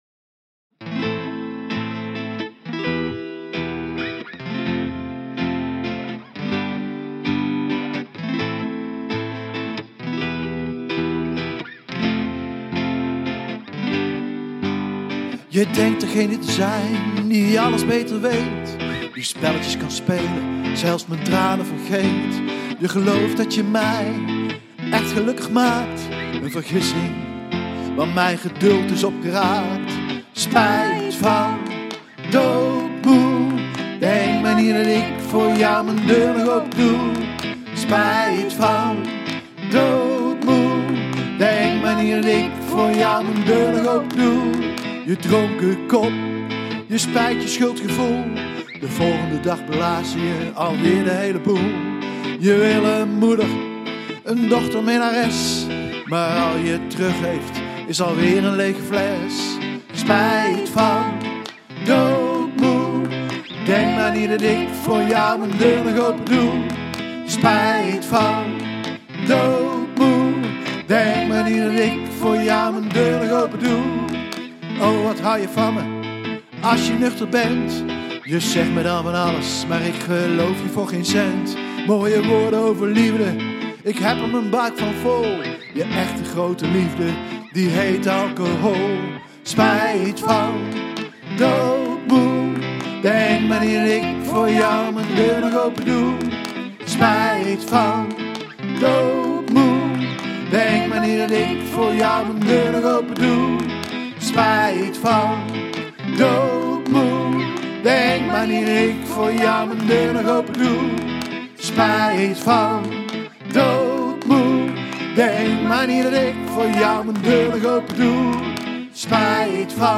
Puur, twee gitaren en twee zangstemmen.